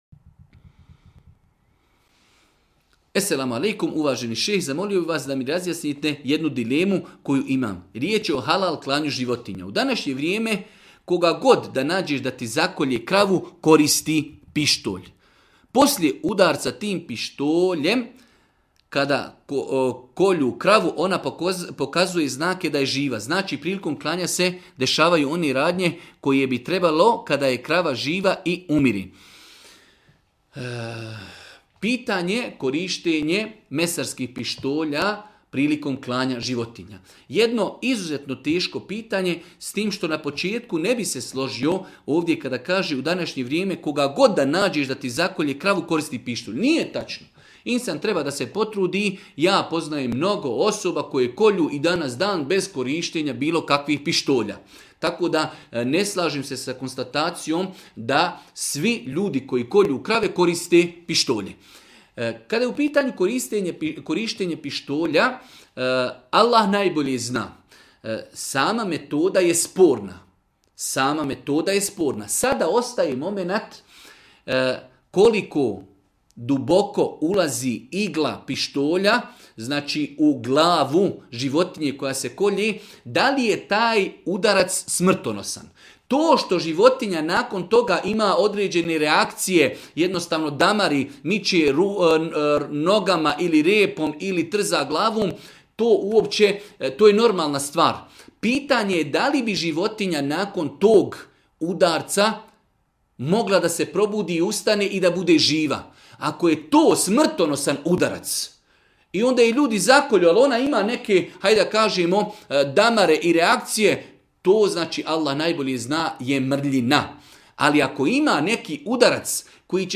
video predavanju